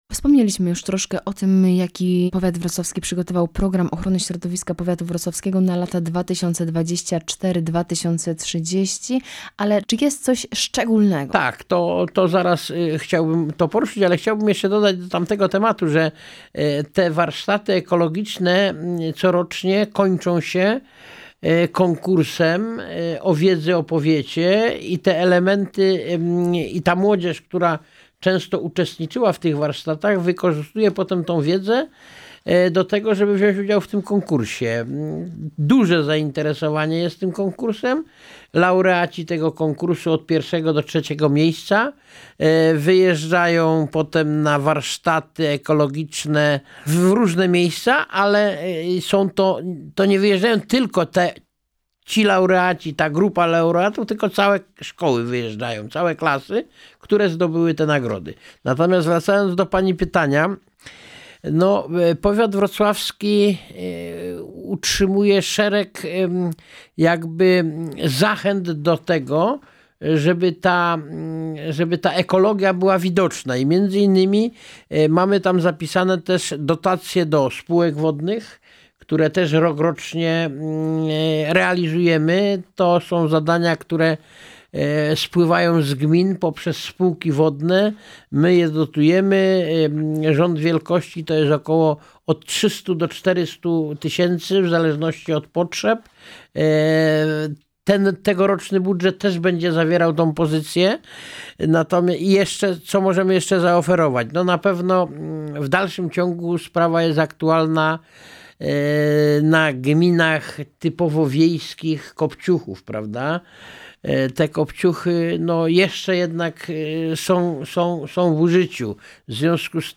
Działania z zakresu Programu Ochrony Środowiska Powiatu Wrocławskiego na lata 2024–2030, inwestycje drogowe, także III forum seniorów Powiatu Wrocławskiego – to tematy poruszane w rozmowie z Wiesławem Zającem – Członkiem Zarządu Powiatu Wrocławskiego.